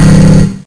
迷你枪
描述：迷你枪射击循环
标签： 机枪
声道立体声